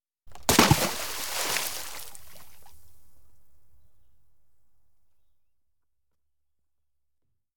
Splash